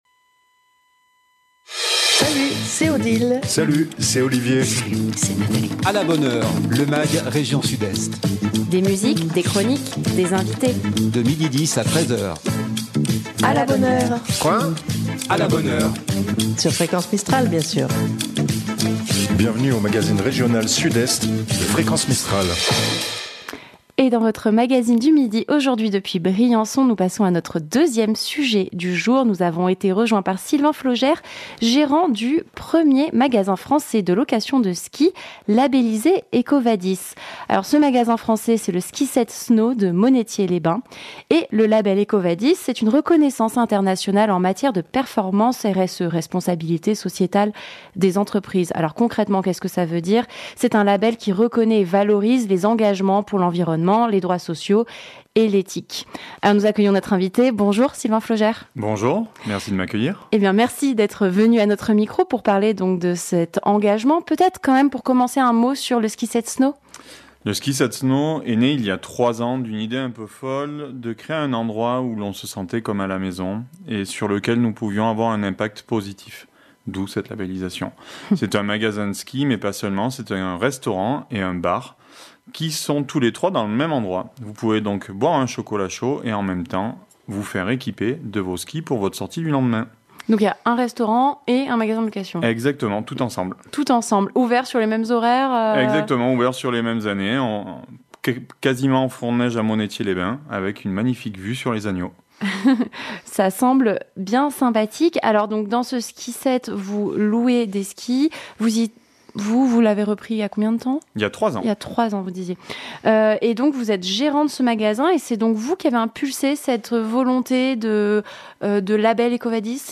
Du lundi au vendredi de 12h10 à 13h " À la bonne heure ! " Bienvenue dans le magazine région Sud-Est de Fréquence Mistral !